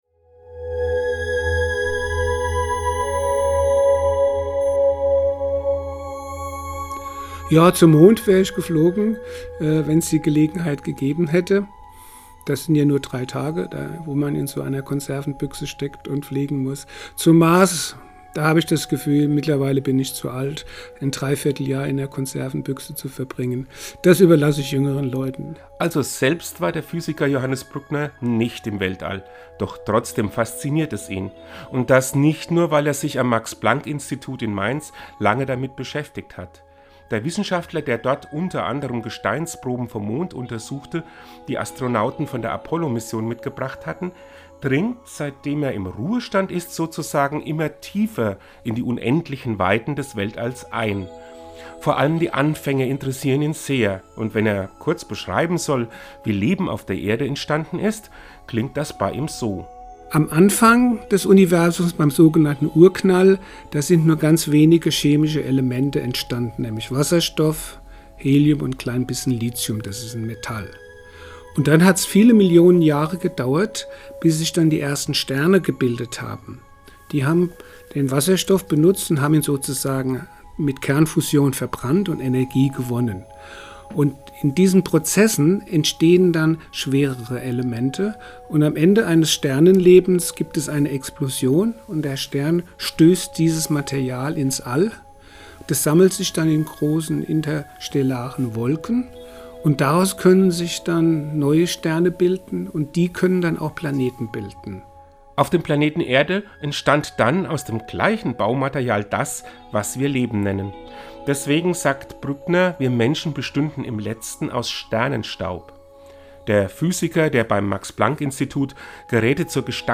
Seinen Radiobeitrag finden Sie unten als Download!